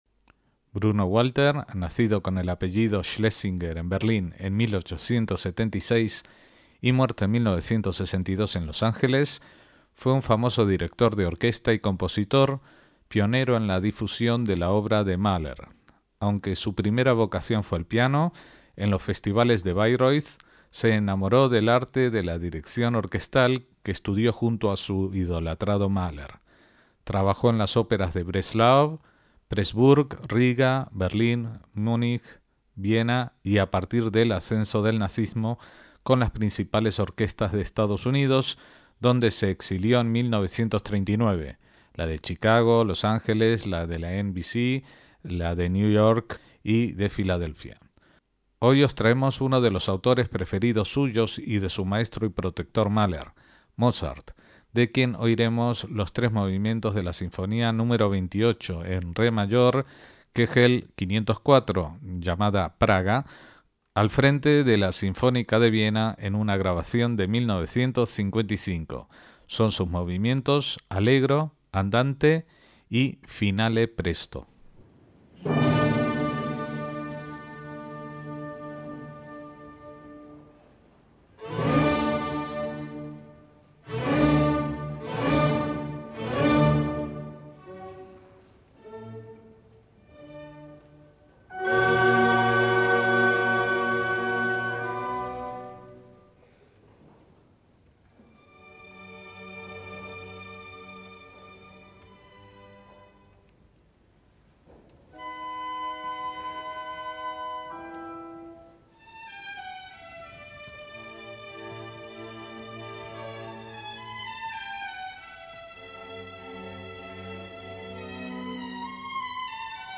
Bruno Walter dirige la Sinfonía nº 38 (“Praga”) de Mozart con la Sinfónica de Viena
MÚSICA CLÁSICA